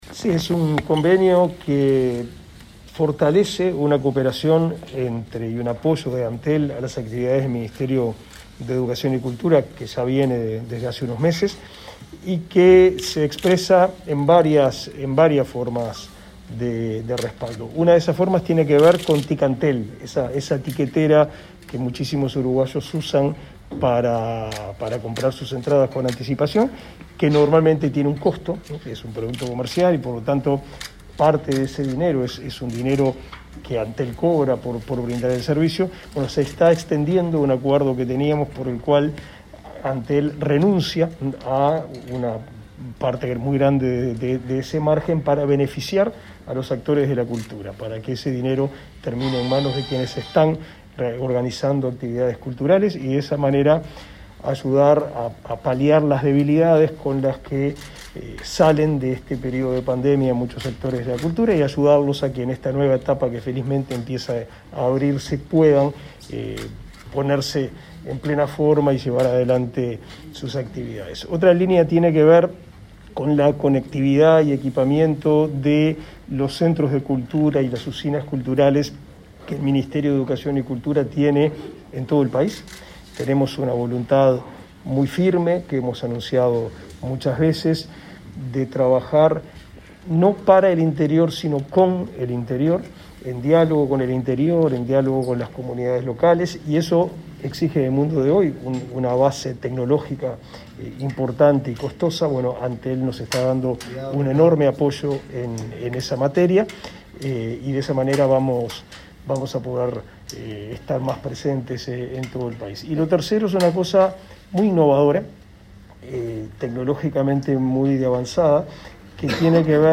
Declaraciones del ministro de Educación y Cultura, Pablo da Silveira
Declaraciones del ministro de Educación y Cultura, Pablo da Silveira 12/08/2021 Compartir Facebook X Copiar enlace WhatsApp LinkedIn Tras participar de una conferencia de prensa en la que se anunciaron apoyos al sector cultural, este jueves 12 de agosto, Da Silveira dialogó con los representantes de los medios.